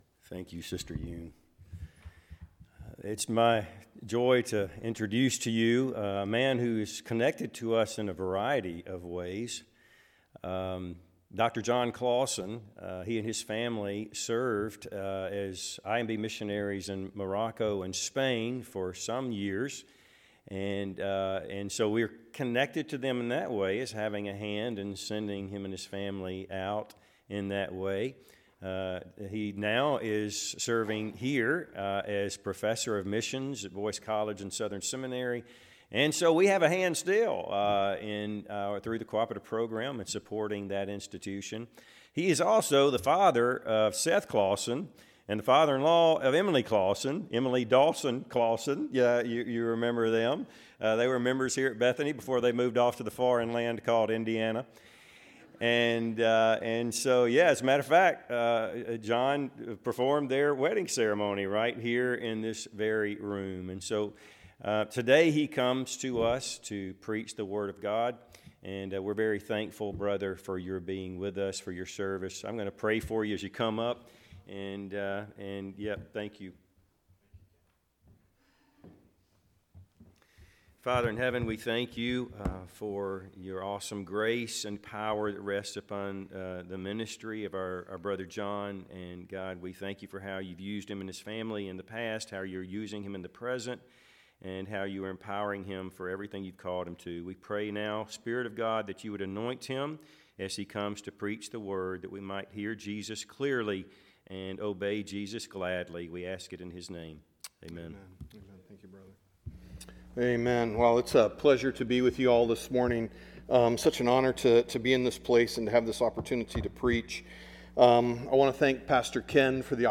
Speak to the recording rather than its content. Passage: Exodus 3:1-15 Service Type: Sunday AM